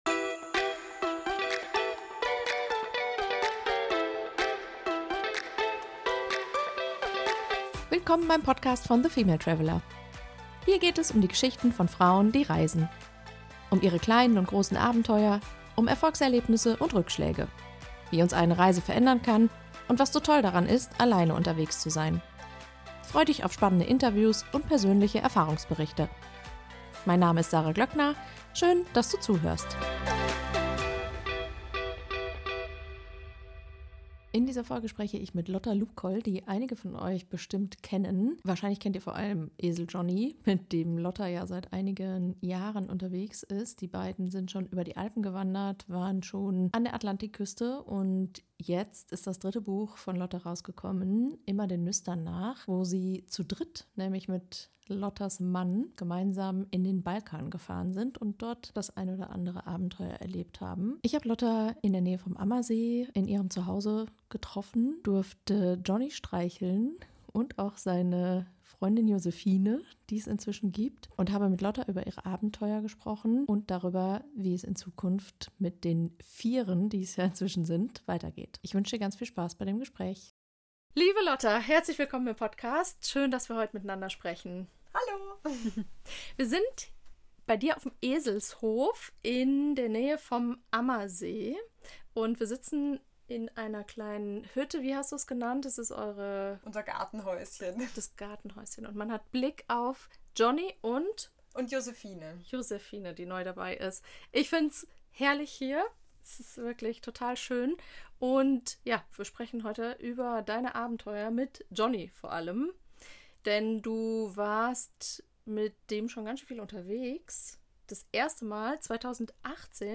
Abenteuer mit Esel - im Gespräch